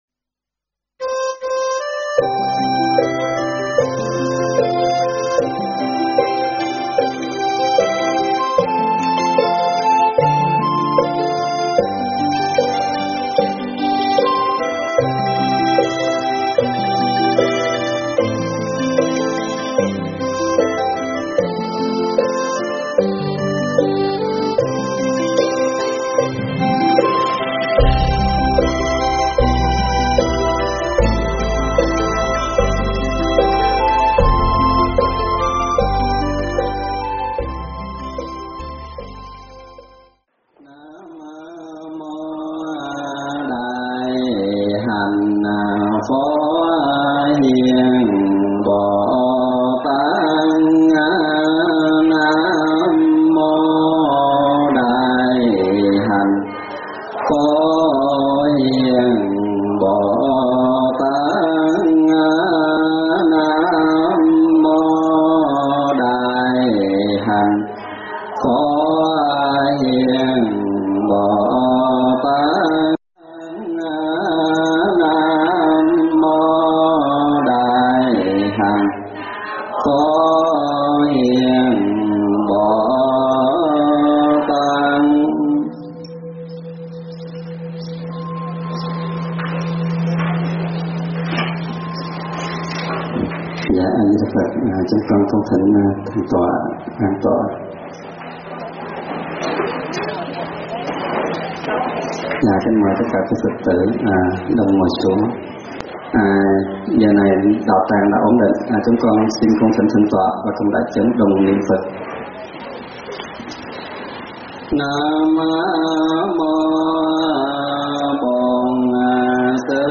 Thuyết pháp Sự Hành Trì Hằng Ngày
giảng tại Chùa Lâm Tỳ Ni, Lawrence, MA